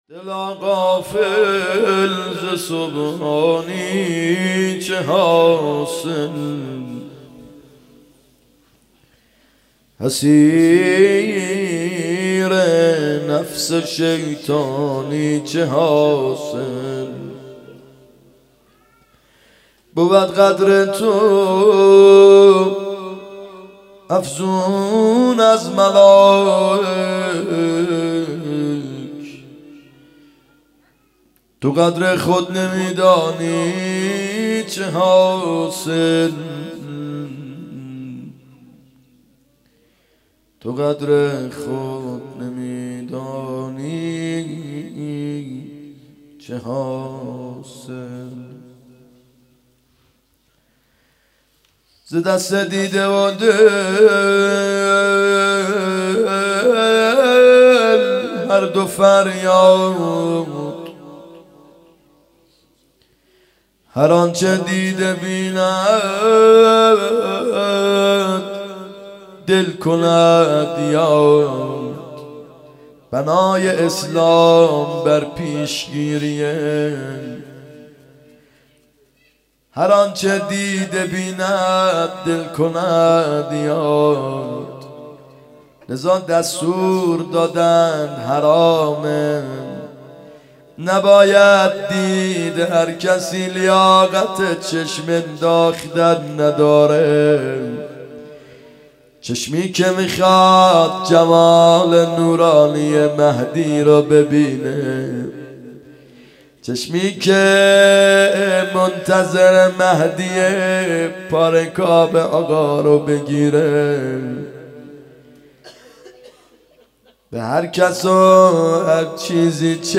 شهادت امام باقر ع